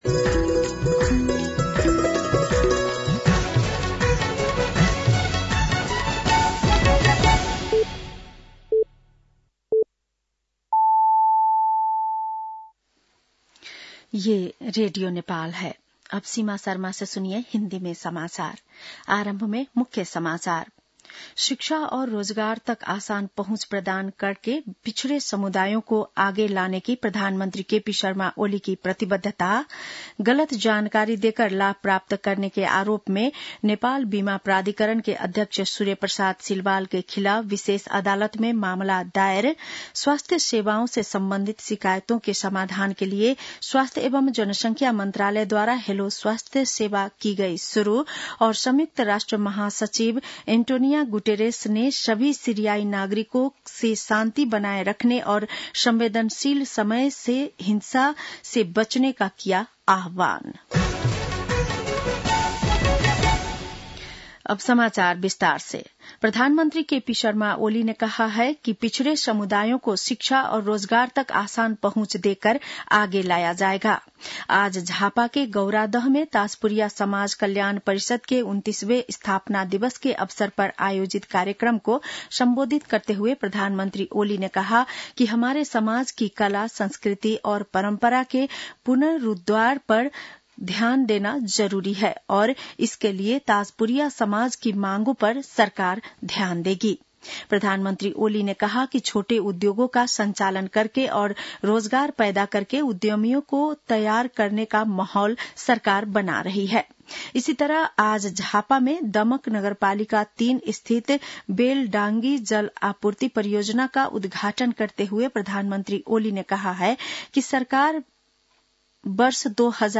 बेलुकी १० बजेको हिन्दी समाचार : २६ मंसिर , २०८१